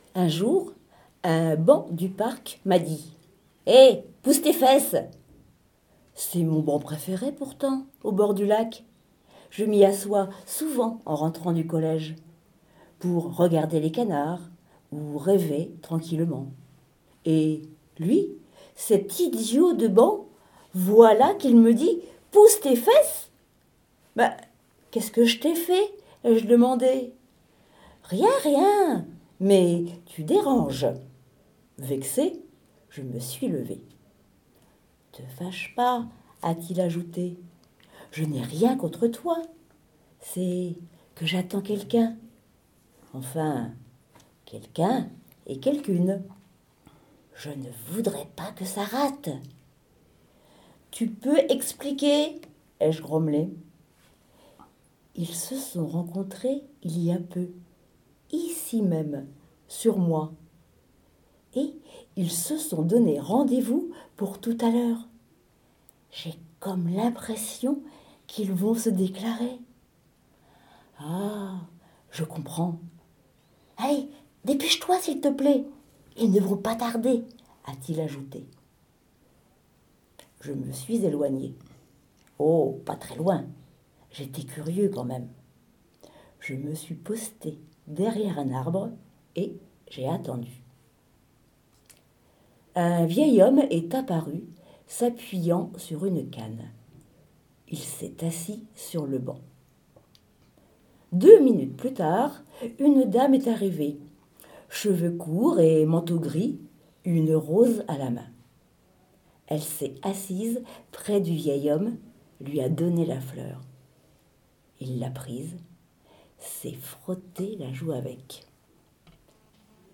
Les hauts parleurs de l’association « Lire à Saint-Lô » prêtent leurs voix sur MDR!